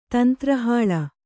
♪ tantra hāḷa